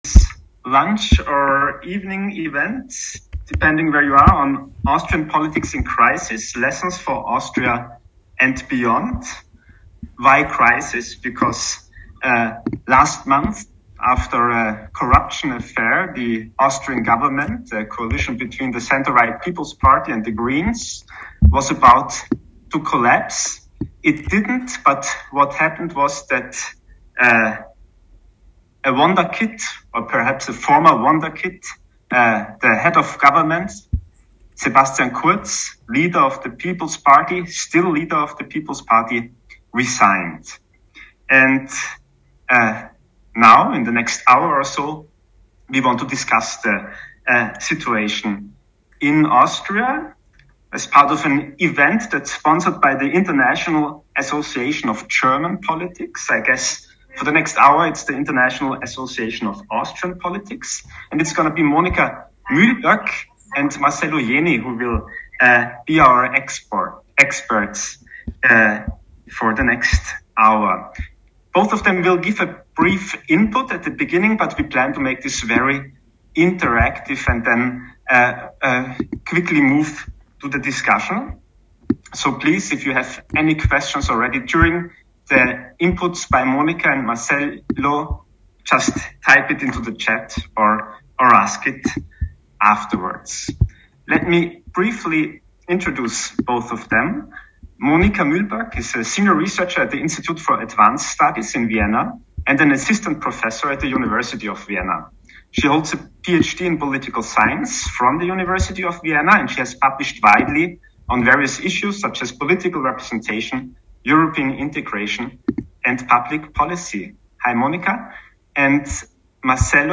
The event will take place on ZOOM on Monday 22 November 2021 at 12:00 EDT | 17:00 GMT | 18:00 CET